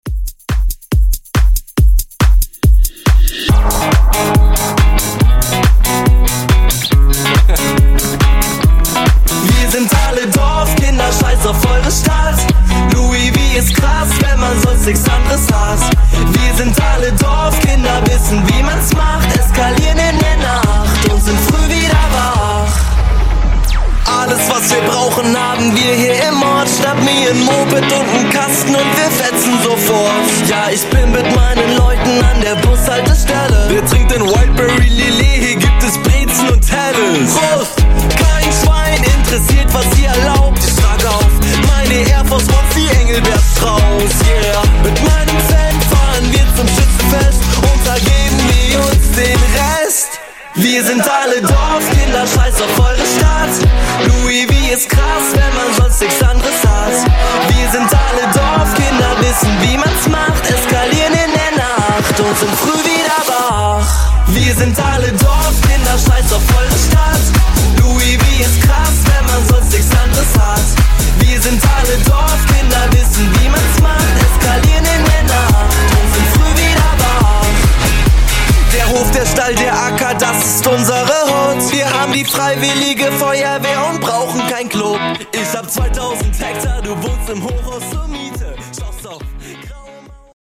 Genres: GERMAN MUSIC , HIPHOP , RE-DRUM
Dirty BPM: 107 Time